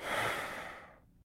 sigh5.ogg